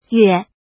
怎么读
yuě huì
yue3.mp3